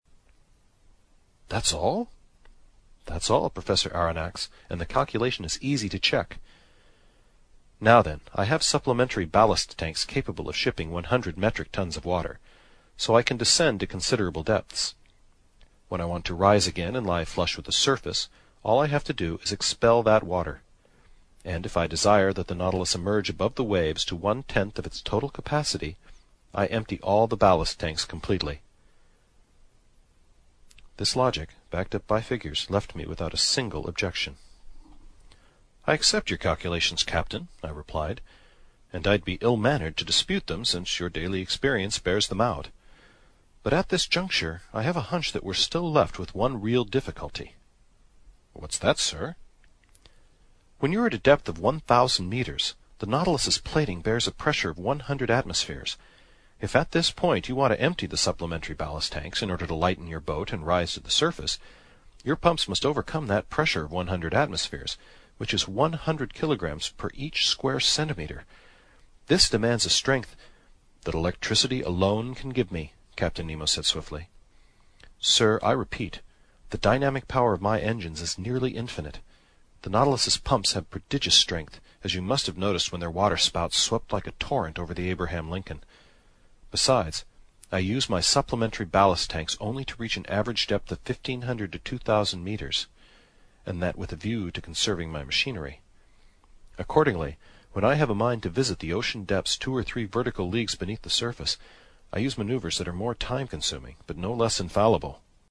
英语听书《海底两万里》第181期 第13章 一些数字(4) 听力文件下载—在线英语听力室